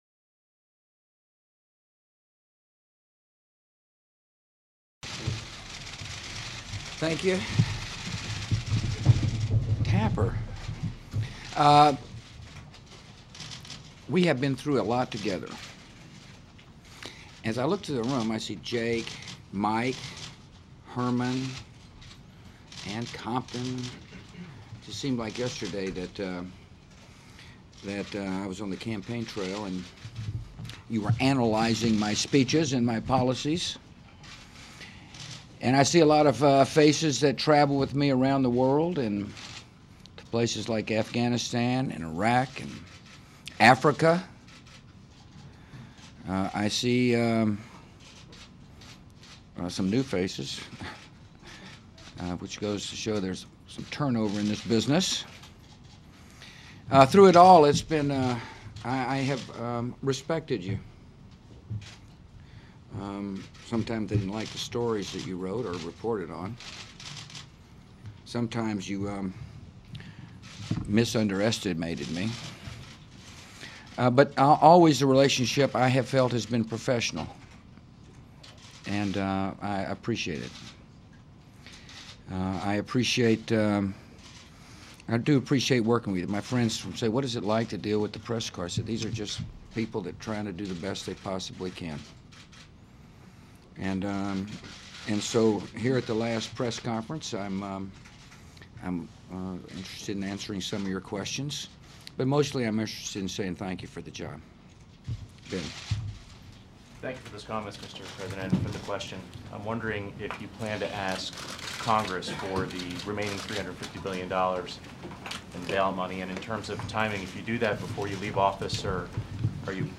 January 12, 2009: Final Press Conference